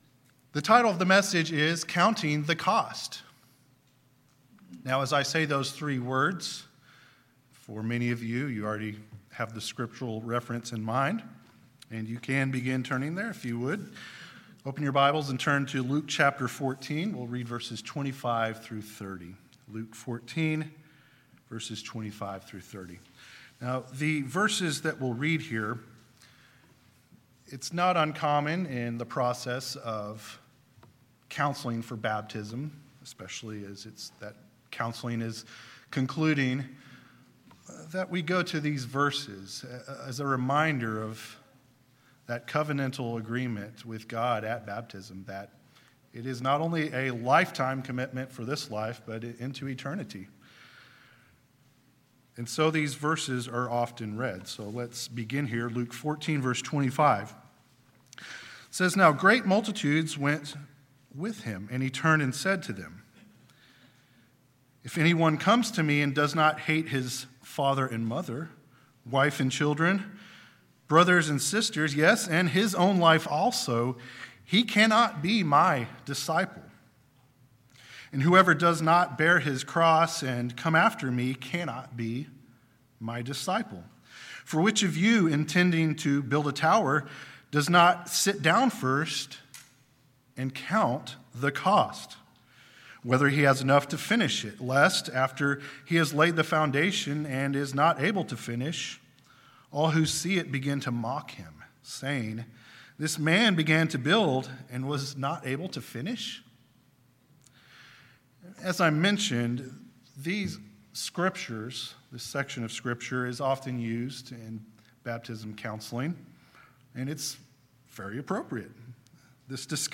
Sermons
Given in East Texas